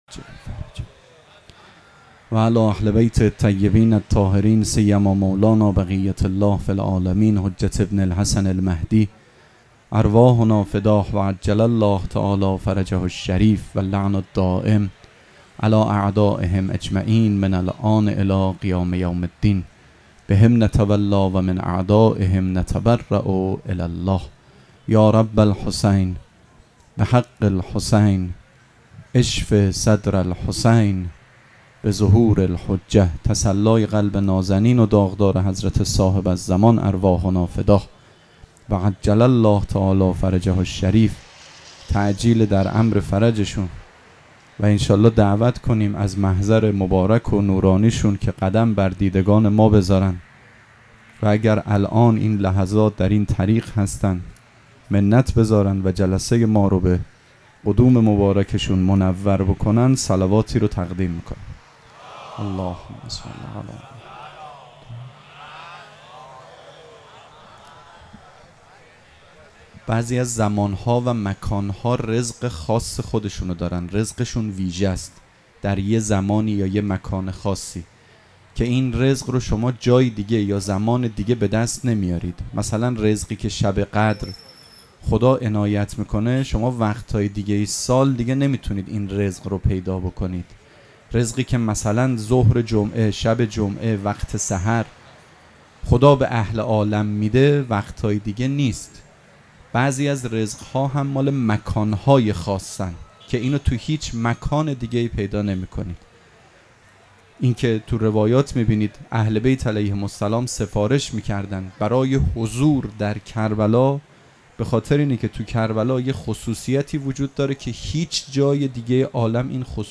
سخنرانی
شب چهارم مراسم عزاداری اربعین حسینی ۱۴۴۷ دوشنبه ۲۰ مرداد ۱۴۰۴ | ۱۷ صفر ۱۴۴۷ موکب ریحانه الحسین سلام الله علیها